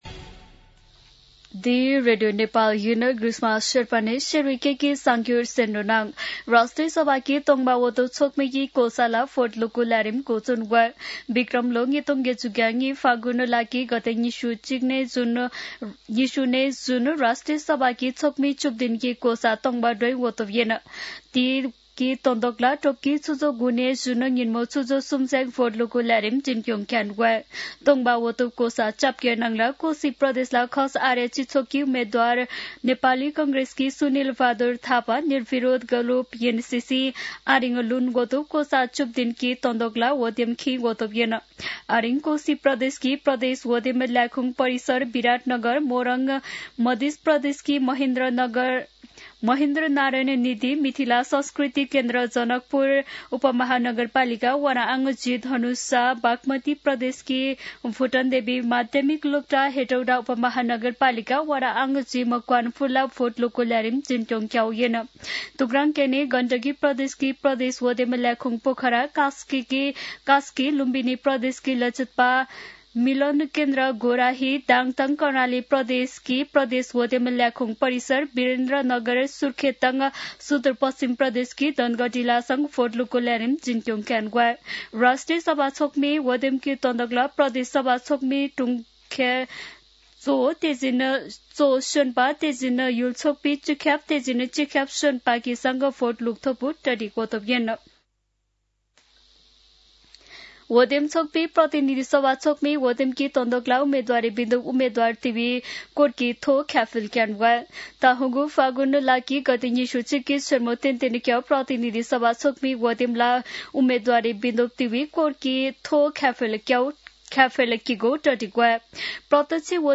शेर्पा भाषाको समाचार : ११ माघ , २०८२
Sherpa-News-10-11.mp3